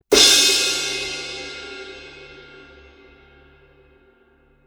Sabian 18" HH Thin Crash Cymbal
The Sabian 18" HH Thin Crash has a low, dark and warm tone with a full-bodied response and a quick decay, making it a very musical cymbal.
Sound: Dark
Weight: Thin